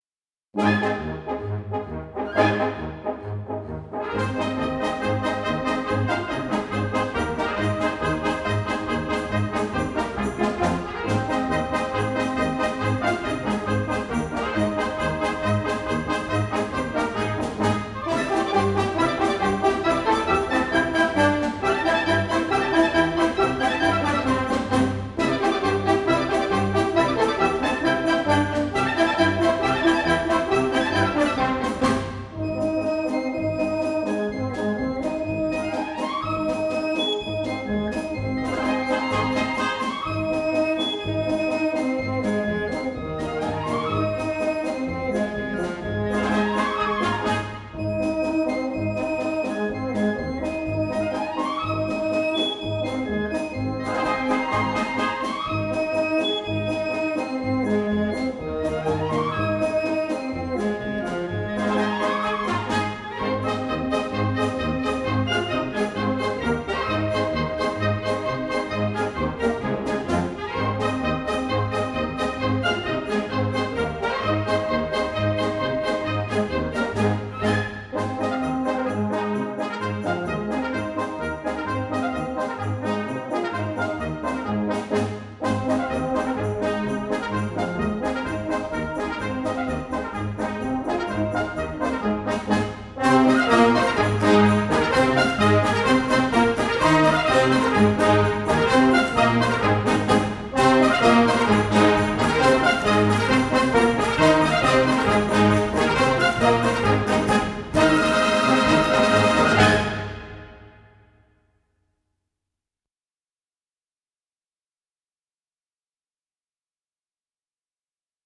军乐进行曲
雄劲刚键的旋律和坚定有力的节奏是进行曲的基本特点。
军乐的主要演奏乐器为音乐器和打击乐器。
军乐一般重声势，旋律明快、高昂，以此起鼓舞和振奋之作用。